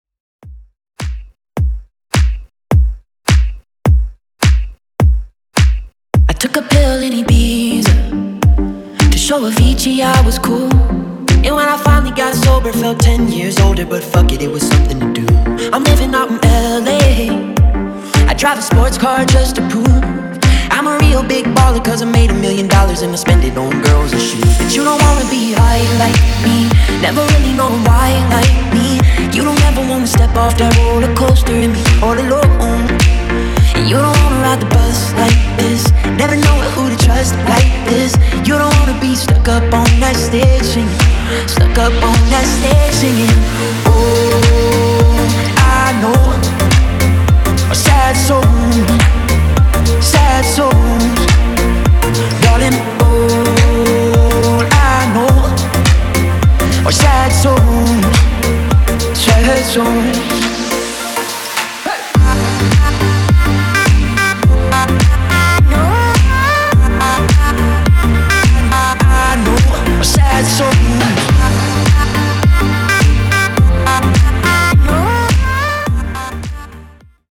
Genres: 2000's , 90's , MASHUPS
Clean BPM: 108 Time